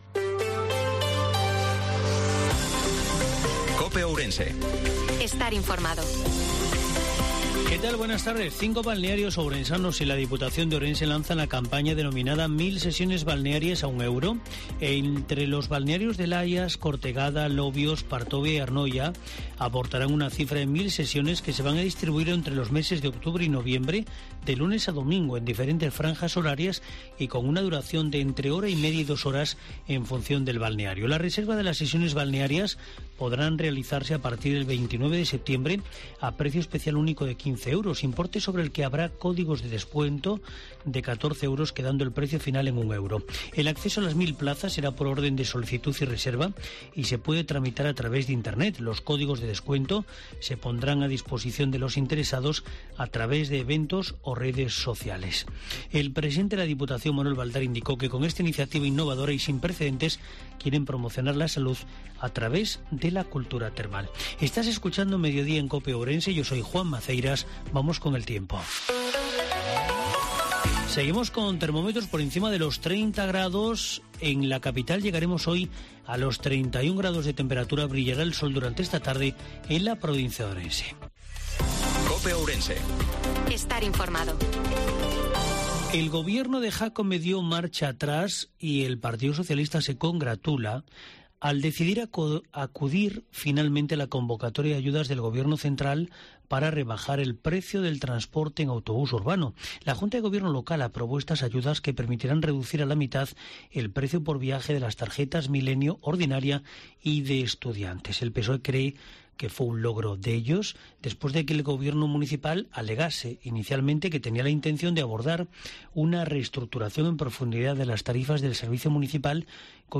INFORMATIVO MEDIODIA COPE OURENSE-22/09/2022